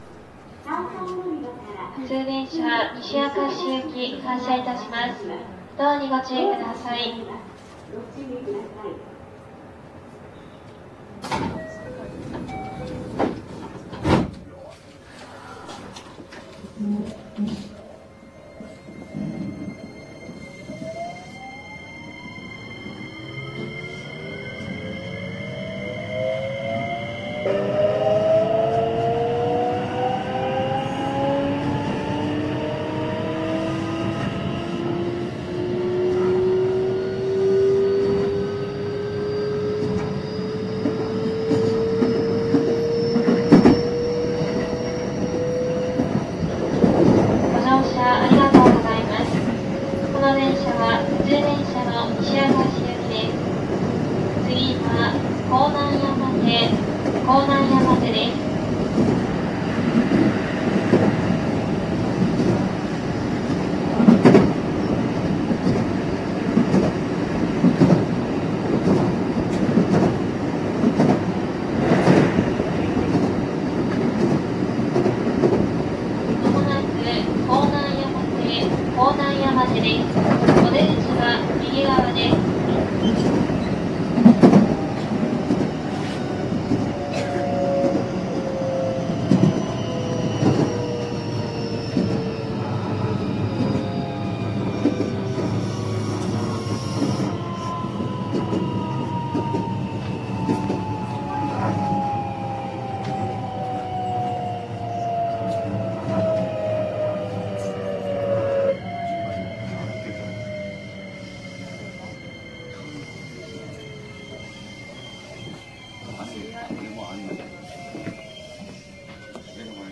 いかにも東洋ＩＧＢＴという変調音が何よりの証拠だと思われます。
走行音（モハ320-31）
収録区間：東海道線 芦屋→甲南山手